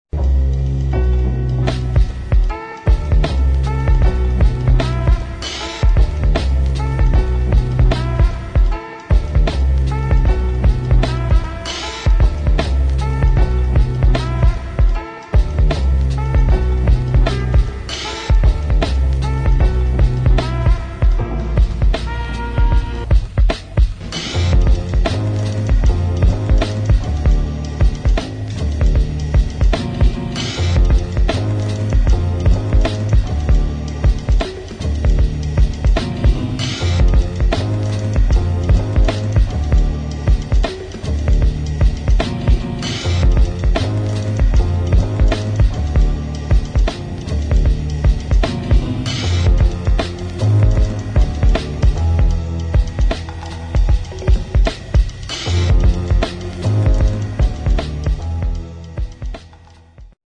[ HIP HOP ]
Remix - Instrumental